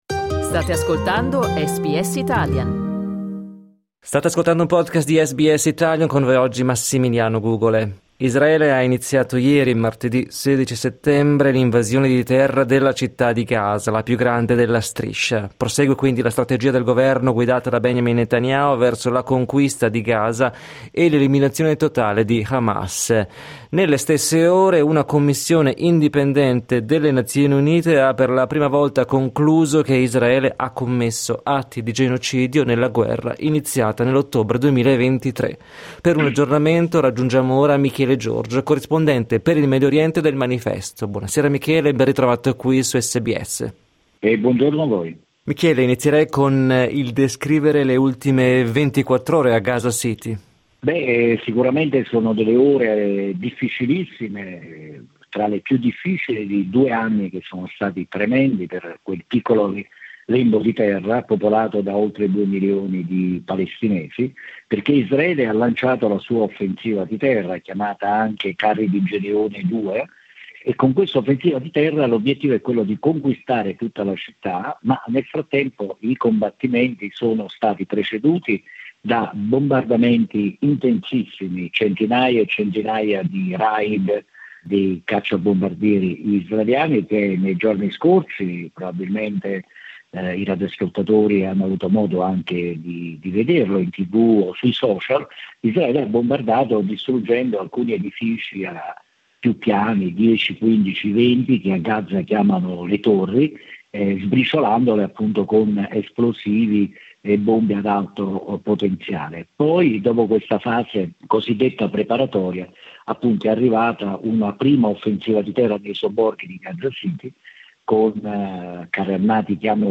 In collegamento da Gerusalemme